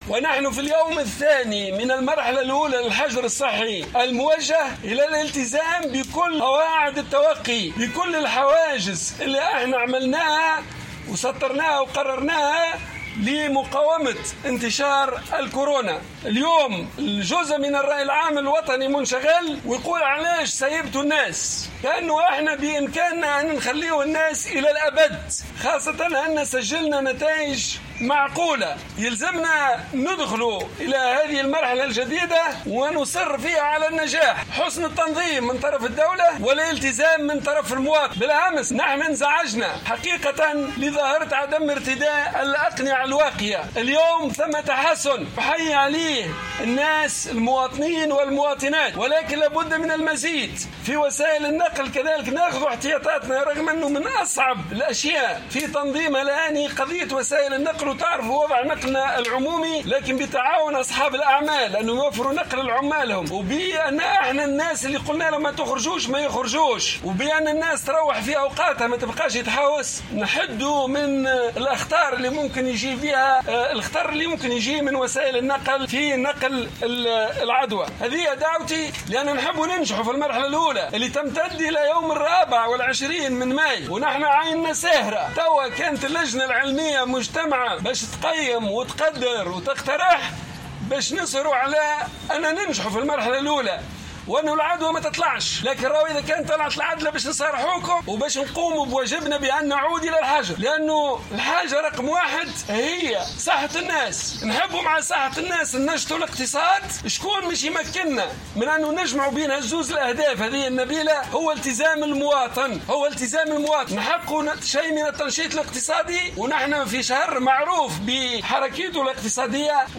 وأشار المكي في نقطة إعلامية تم تنظيمها اليوم بوزارة الصحة ،إلى تسجيل تحسن في ارتداء الكمامات لدى المواطنين مقارنة بيوم أمس في أول أيام الحجر الصحي الموجه ،مضيفا أن الأقنعة الواقية توفر نسبة حماية تقارب 70% ، مذكرا بأهمية غسل اليدين .